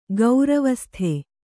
♪ gauravasthe